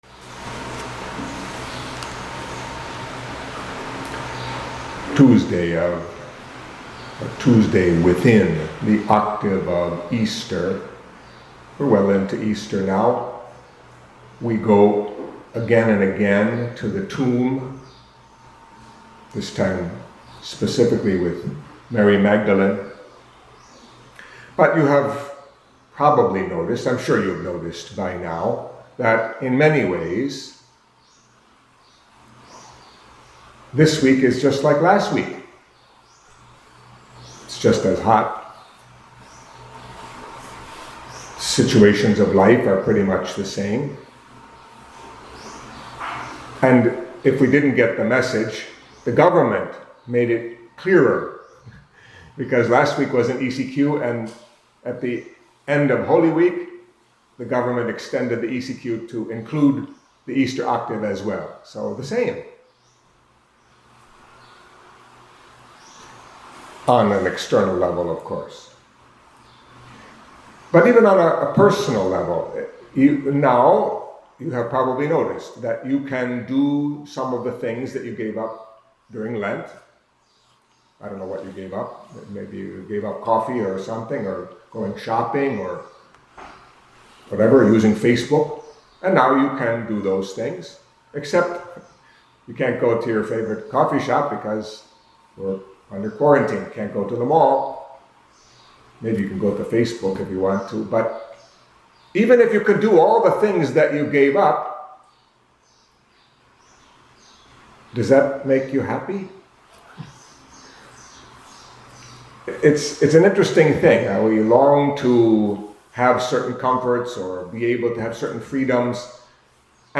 Catholic Mass homily for Easter Tuesday